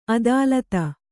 ♪ adālata